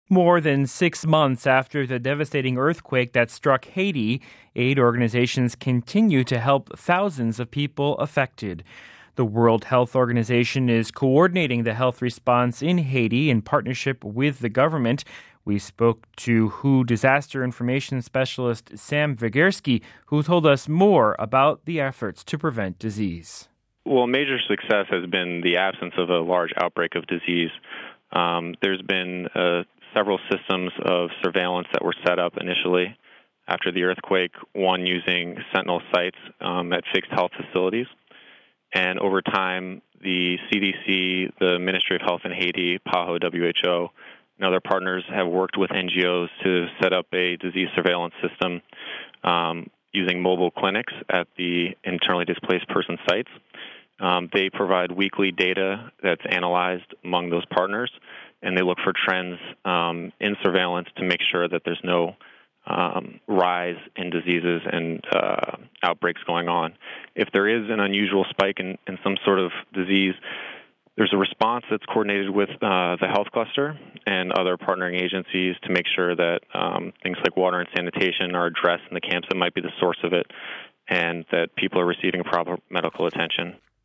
We spoke to WHO disaster information specialist